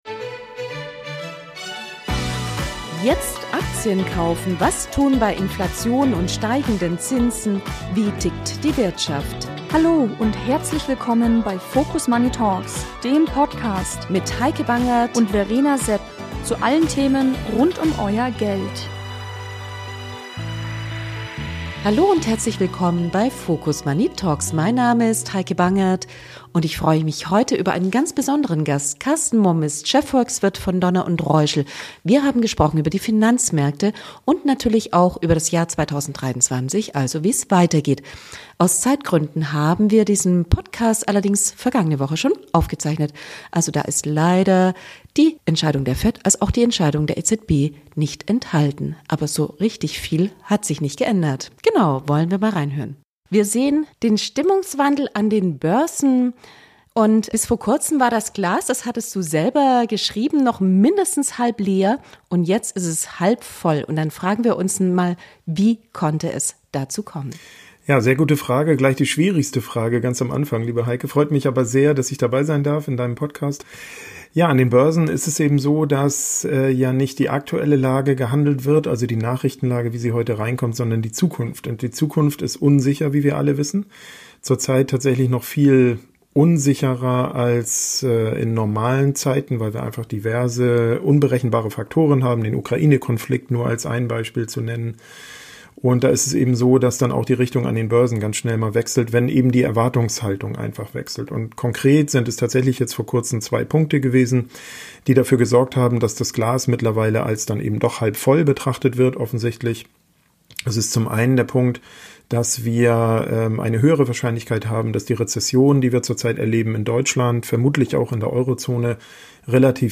#19 Interview